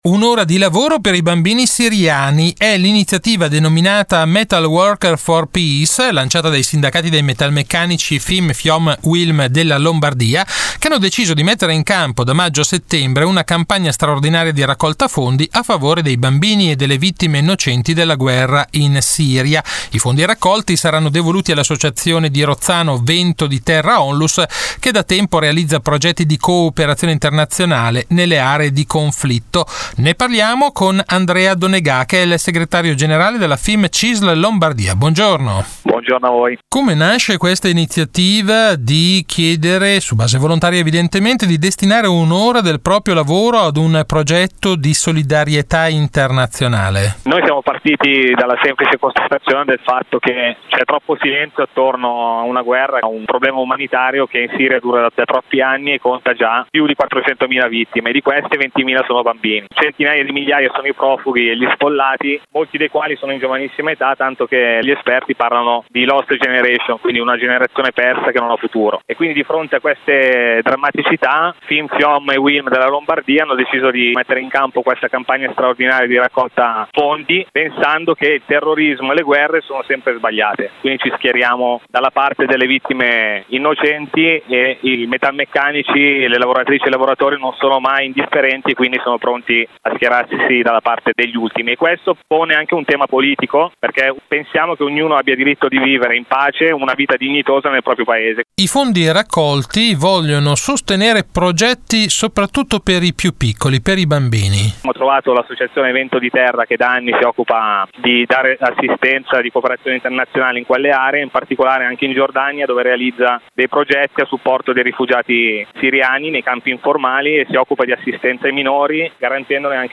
Di seguito l’ultima puntata di RadioLavoro, la rubrica d’informazione realizzata in collaborazione con l’ufficio stampa della Cisl Lombardia e in onda tutti i giovedì alle 18.20 su Radio Marconi in replica il venerdì alle 12.20.